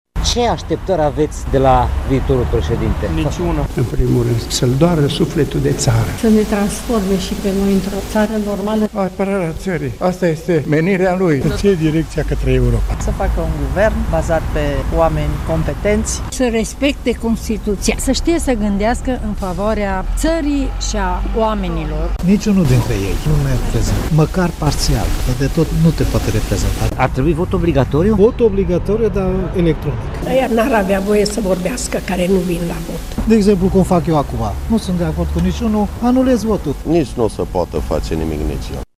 Așa cred tg.mureșenii ieșiți azi la vot. Unii sunt dezamăgiți de ambii candidați și nu au mai mers la vot sau și-au anulat votul: